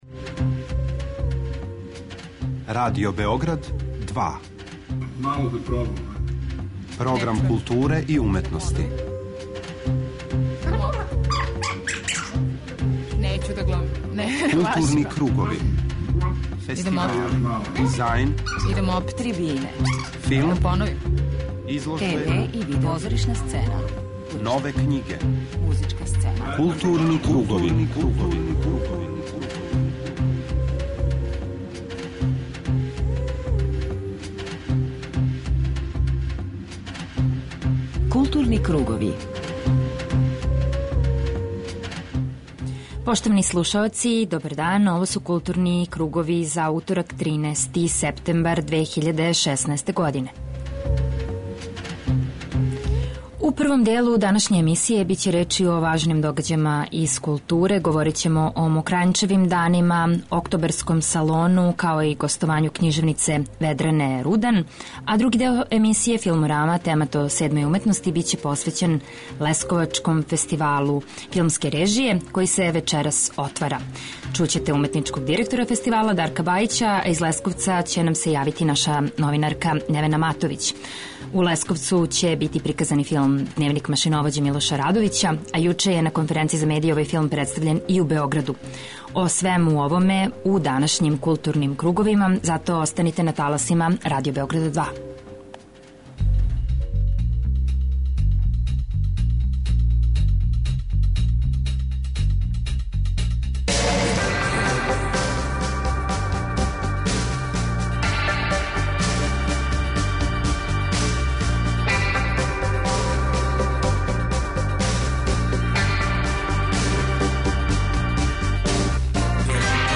У данашњој емисији чућете звучне белешке са ове конференције, као и разговоре са глумцима и редитељем филма.